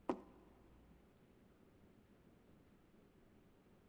FootstepHandlerPlastic2.wav